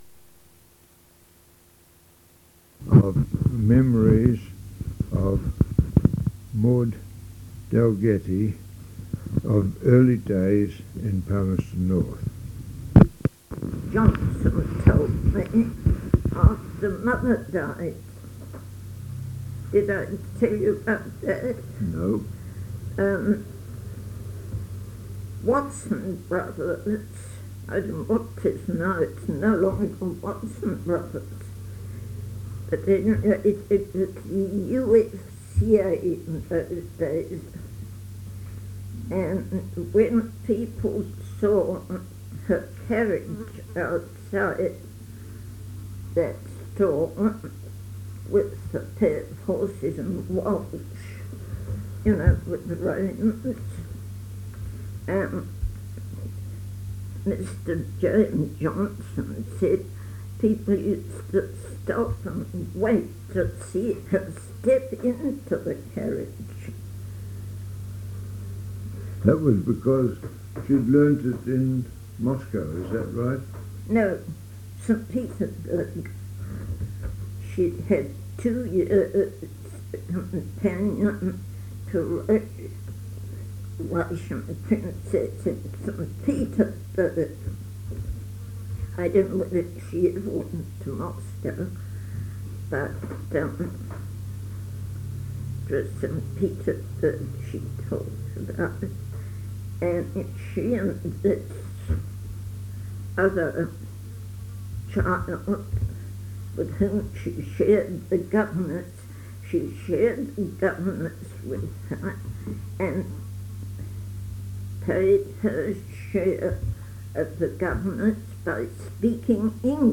Unknown interviewer.